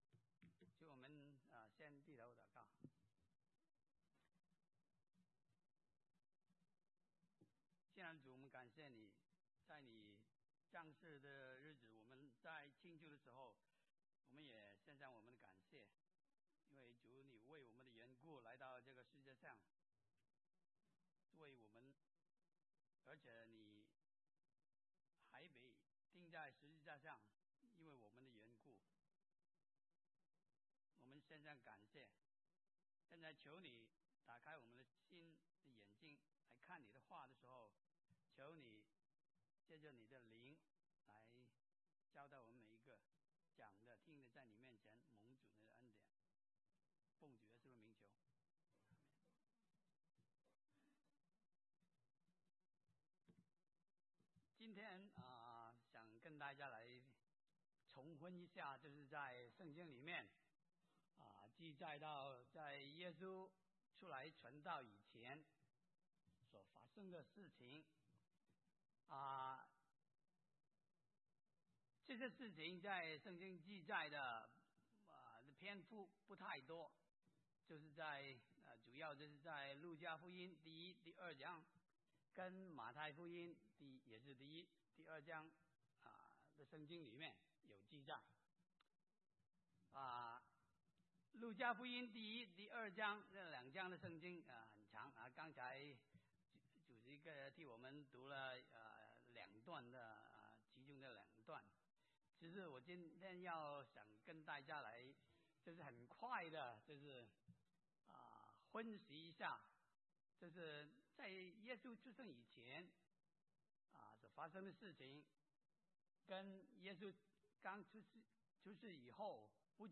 » 下載錄音 (很抱歉，这段录音有一些录制上的困难)